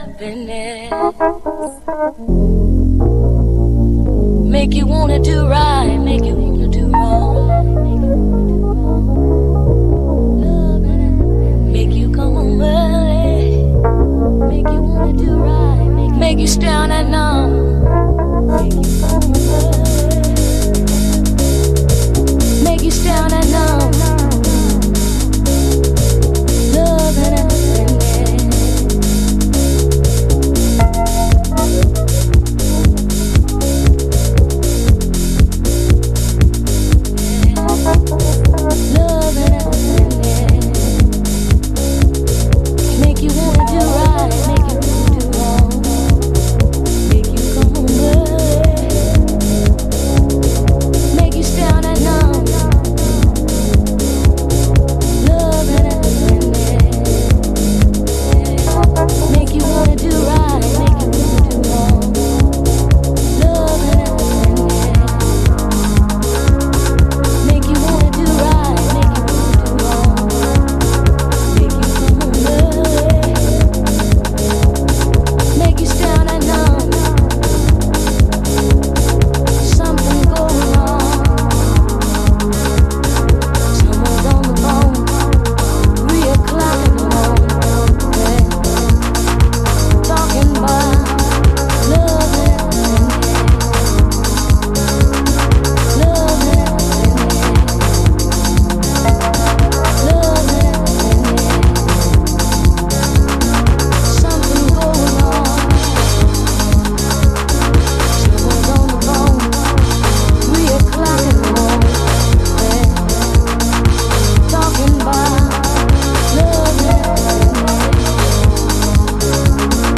House / Techno
Vocal